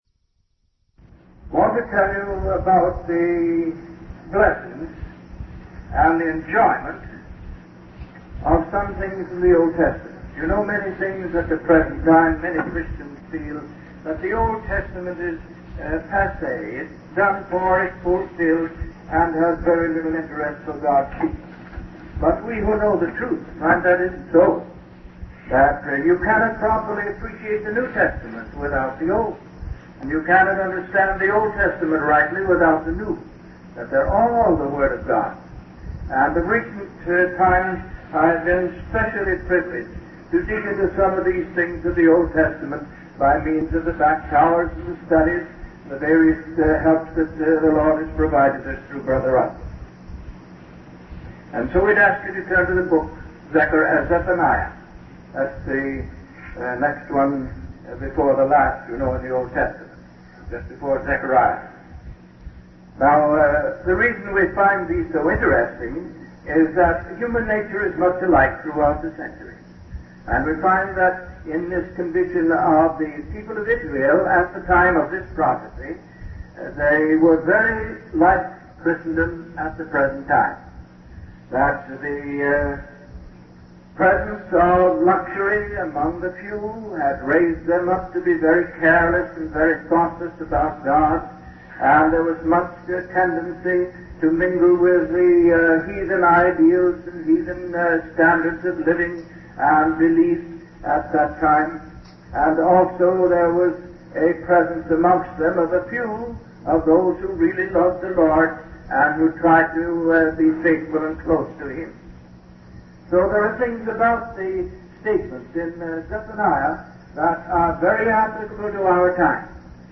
From Type: "Discourse"
2016 The Message of Zephaniah Listen Given in Ft. Worth, TX